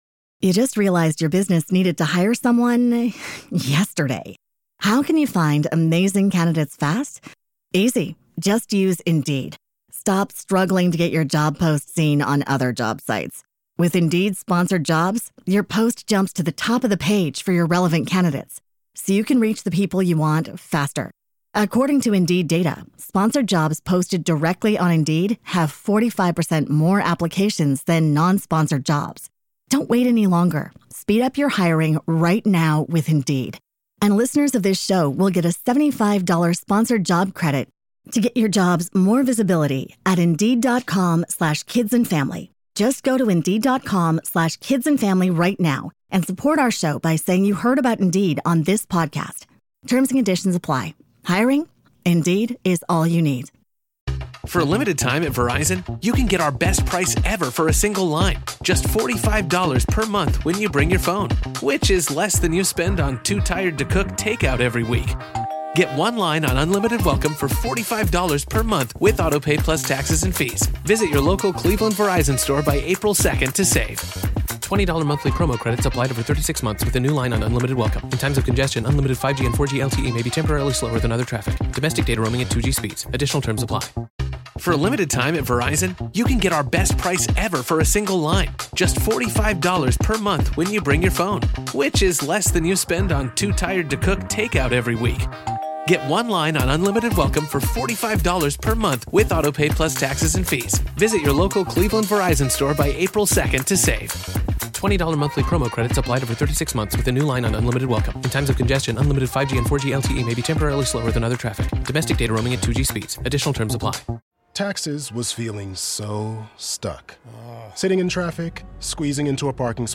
Birdland Tonight is a Baltimore Orioles Postgame Show. Birdland Tonight features a collection of hosts from Baltimore Orioles podcasts and blogs.